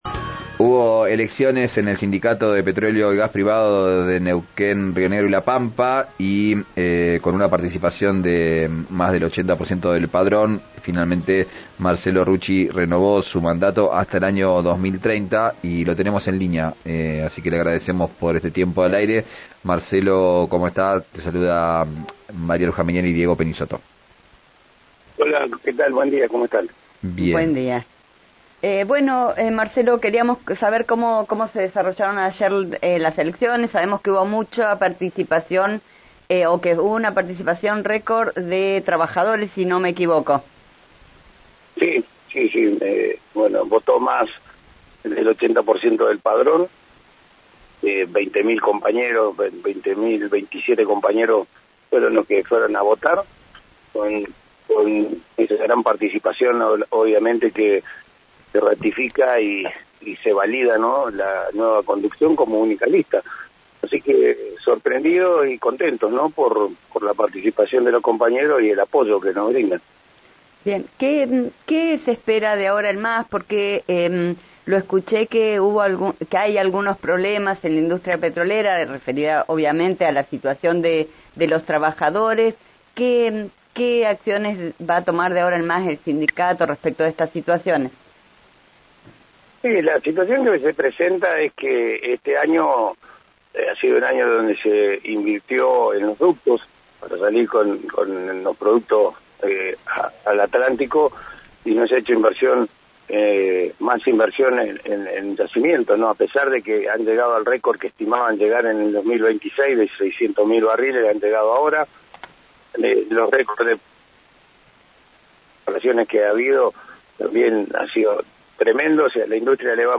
En diálogo con RÍO NEGRO RADIO, el dirigente dijo esta mañana que en la reunión se va a conversar «la modalidad y qué día va a ser».